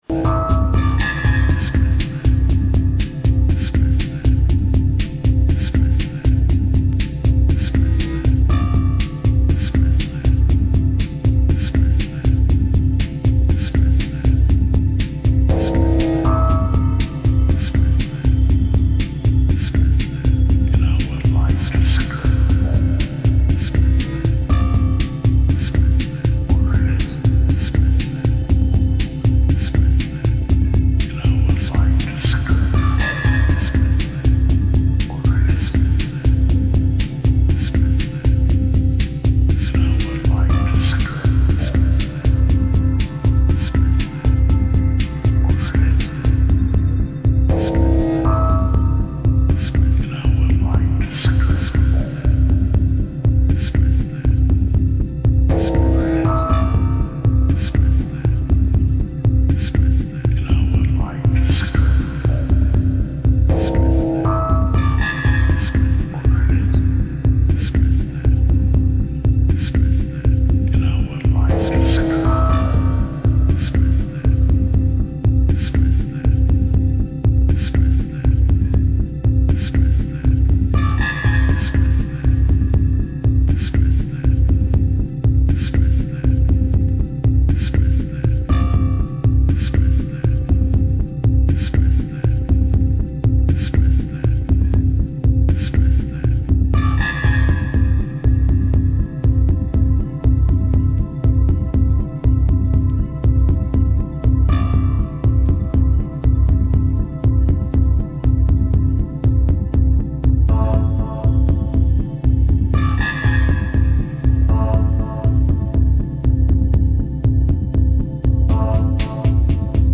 super stripped-back